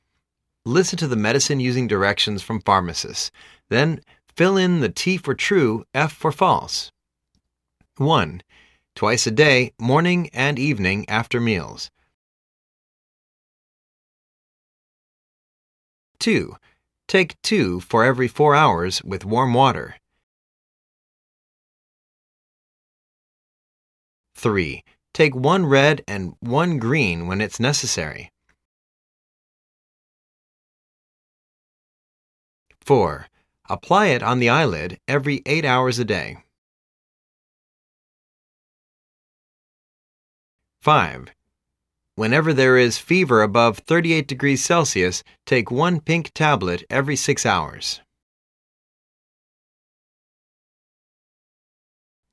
Listen to the medicine using directions from pharmacists.